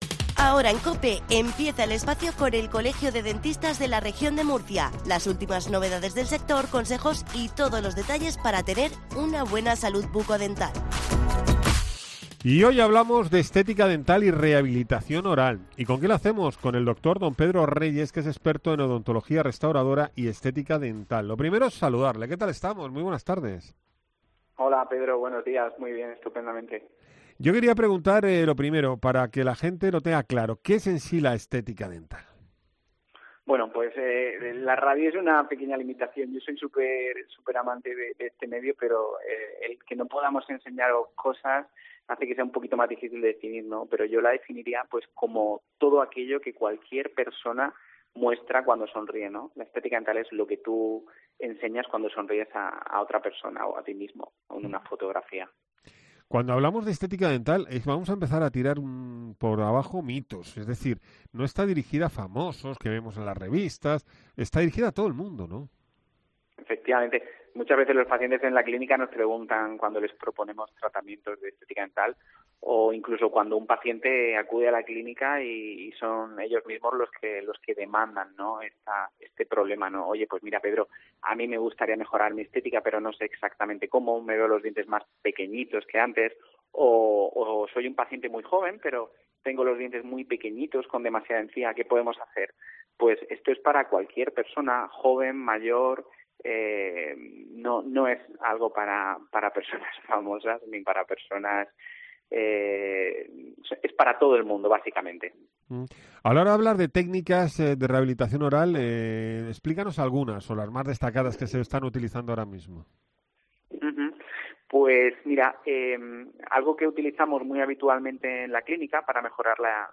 ENTREVISTA ODONTÓLOGOS